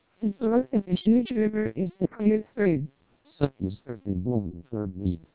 Additionally, the TWELP vocoder features an NCSE (Noise Cancellation Speech Enhancement) preprocessor, which cleans the input speech signal from noise and enhances speech quality.
Below, you can listen to a short fragment of heavily noisy English speech after passing through MELPe and TWELP vocoders, with NPP (Noise Pre-Processor) and NCSE disabled and enabled, respectively.
twelp300_ae_short_snr10db_ncse.wav